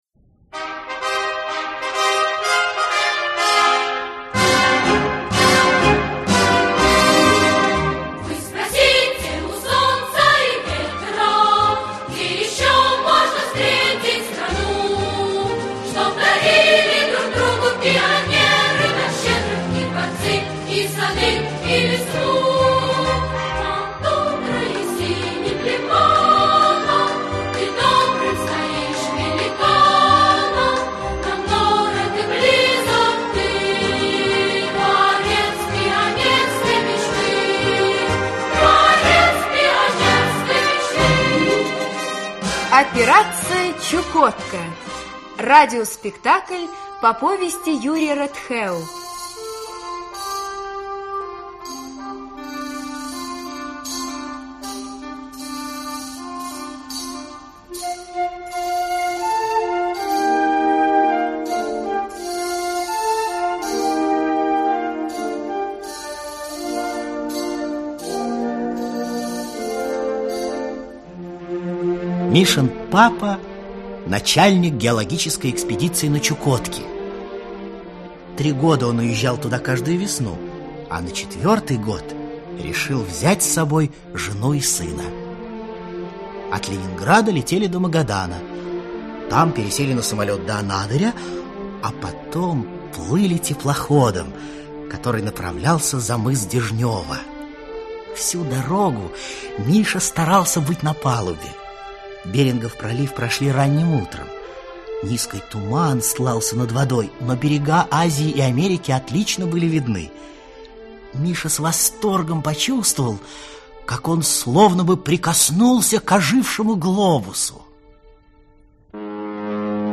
Аудиокнига Операция «Чукотка» | Библиотека аудиокниг
Aудиокнига Операция «Чукотка» Автор Юрий Рытхэу Читает аудиокнигу Актерский коллектив.